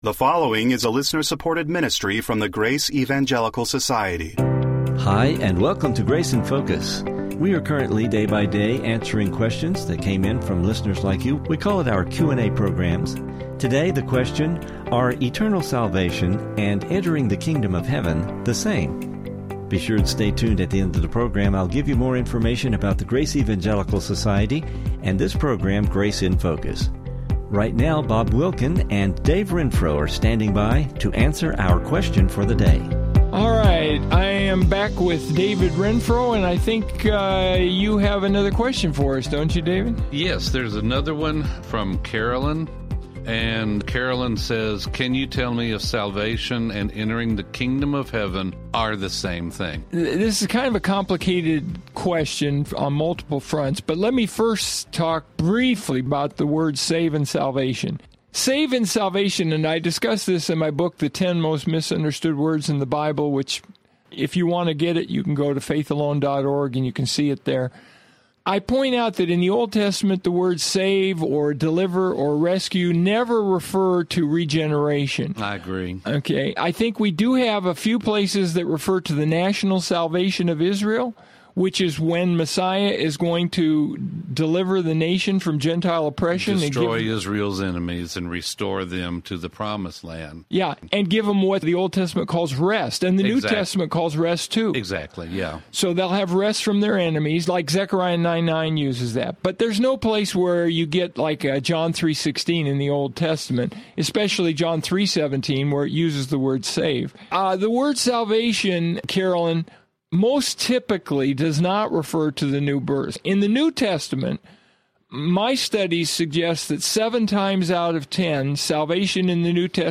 Second, we will hear the guys discuss the concept of “entering” the Kingdom of Heaven.